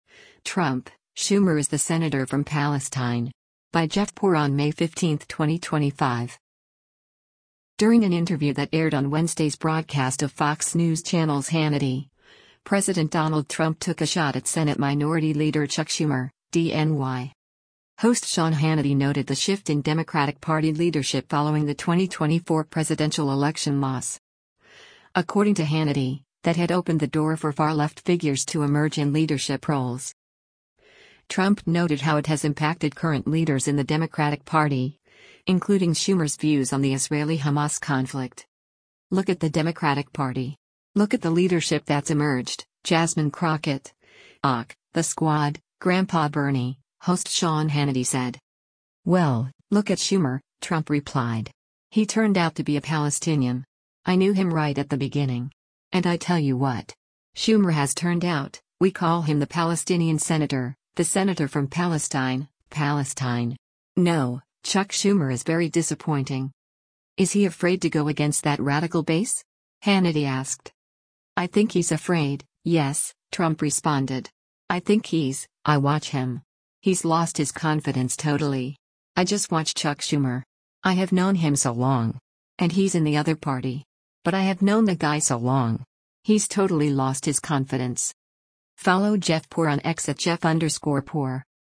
During an interview that aired on Wednesday’s broadcast of Fox News Channel’s “Hannity,” President Donald Trump took a shot at Senate Minority Leader Chuck Schumer (D-NY).